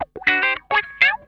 CRUNCHWAH 11.wav